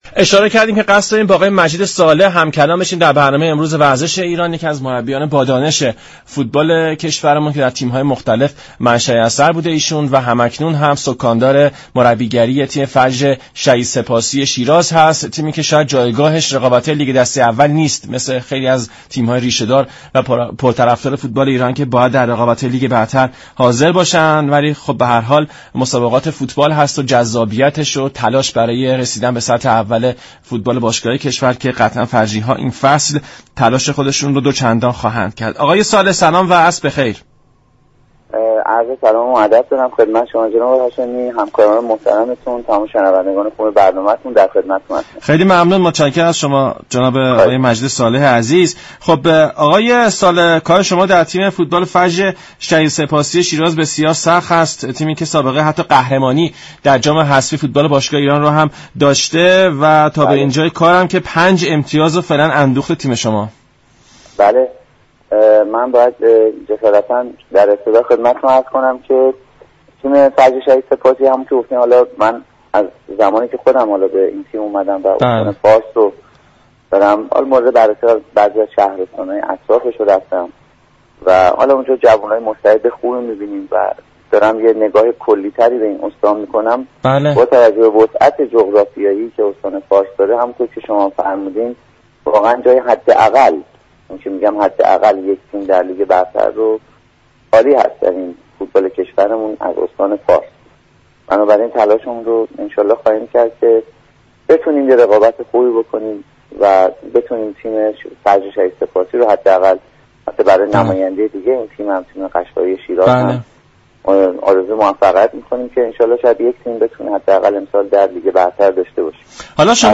در گفت و گو با برنامه «ورزش ایران»